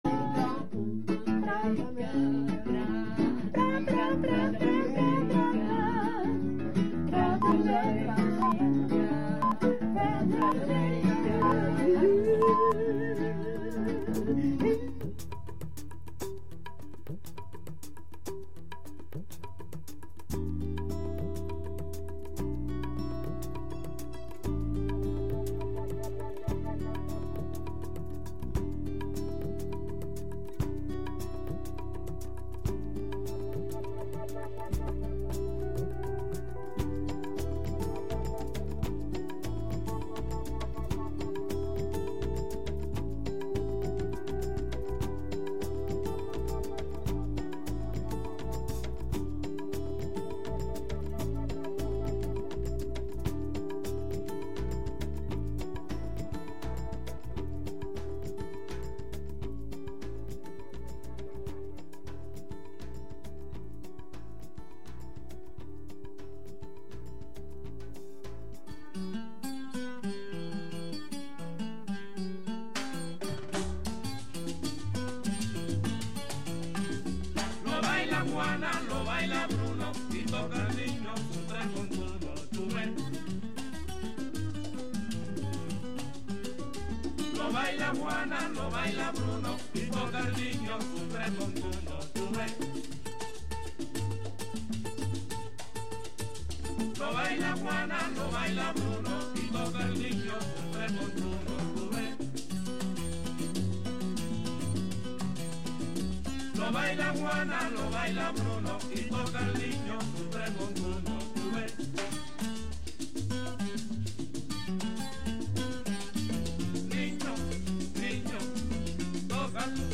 Recorreguts musicals pels racons del planeta, música amb arrels i de fusió.